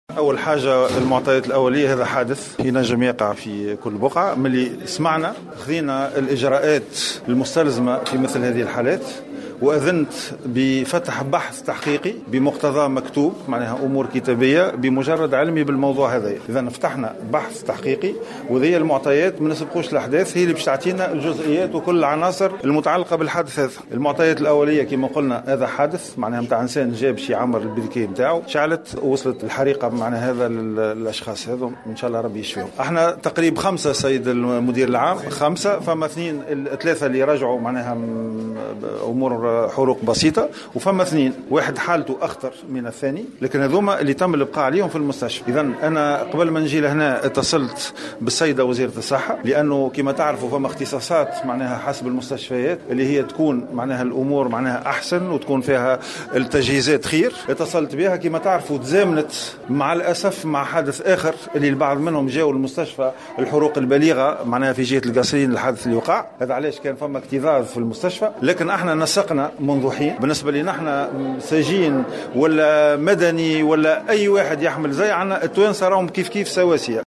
نشرة أخبار السابعة مساء ليوم الخميس غرة سبتمبر 2016